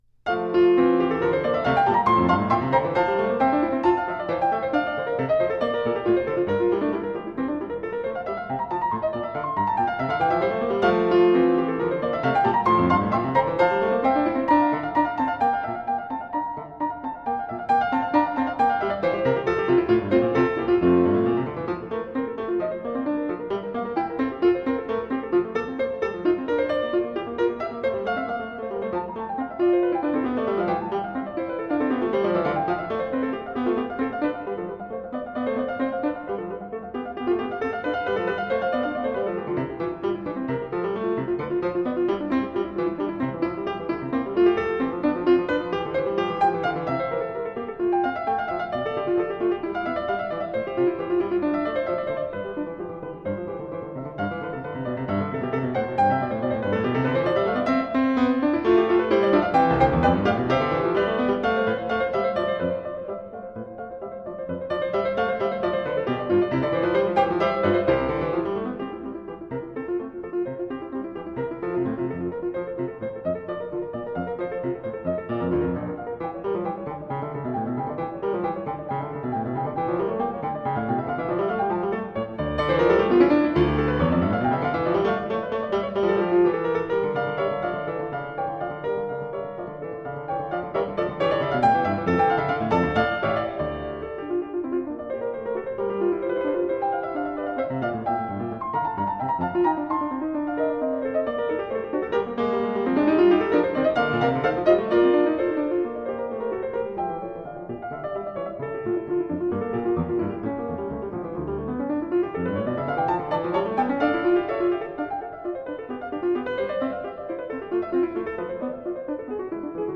Concerto